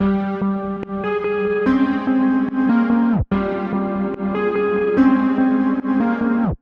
陷阱旋律 G 145 bpm
描述：我做了一个快速的小旋律。
Tag: 145 bpm Trap Loops Synth Loops 1.11 MB wav Key : G